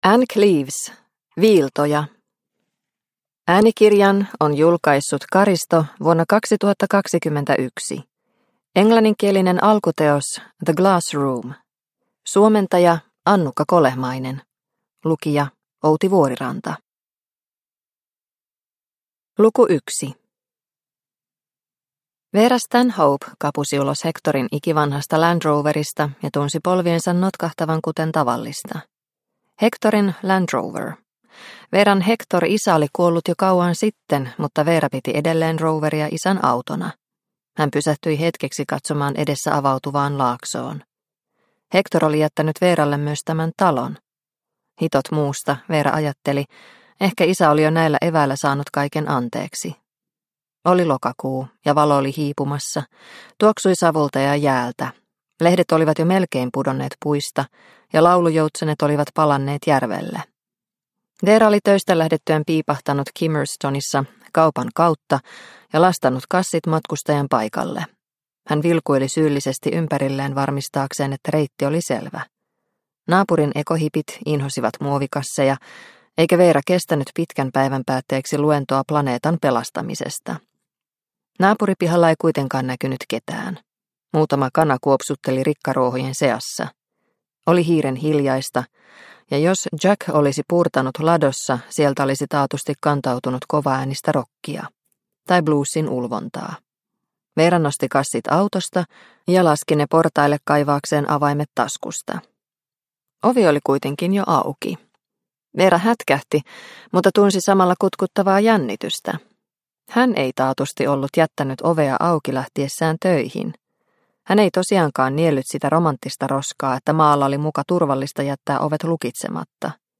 Viiltoja – Ljudbok – Laddas ner